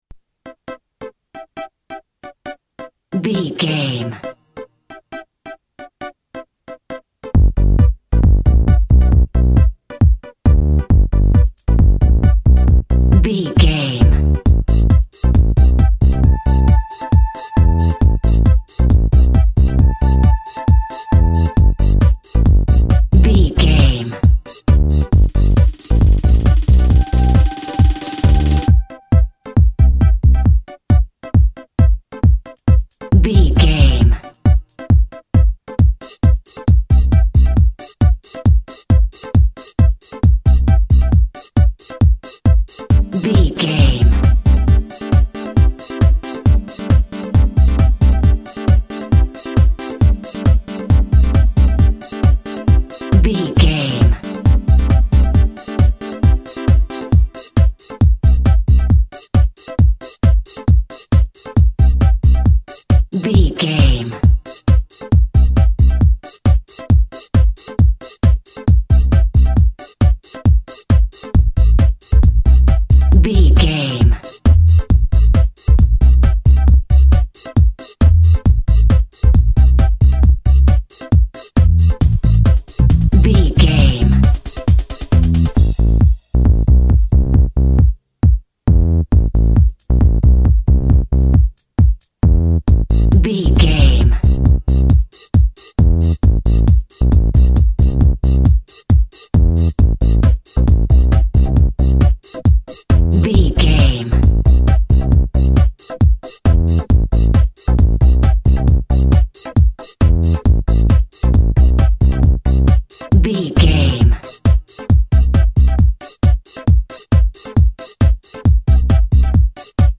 House Music from Spain.
Fast paced
Aeolian/Minor
funky
groovy
uplifting
driving
energetic
electronic
synth lead
synth bass
Electronic drums
Synth pads